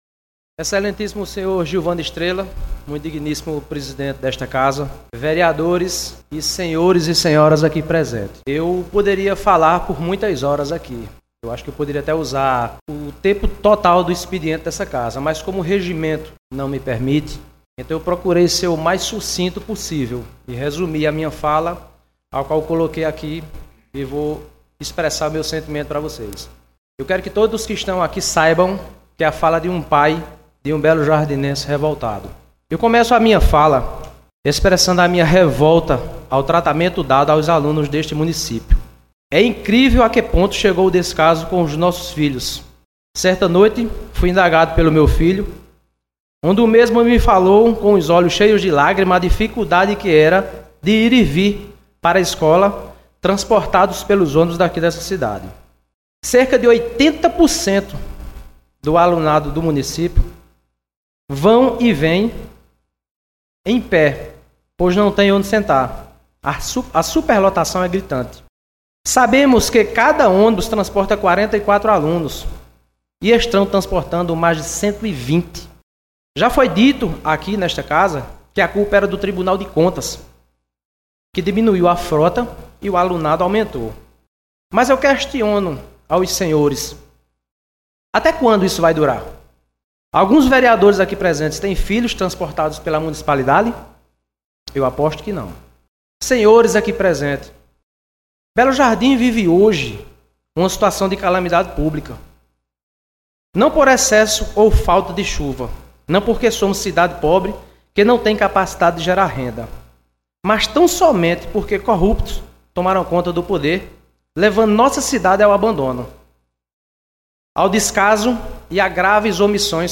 Confira o áudio do discurso: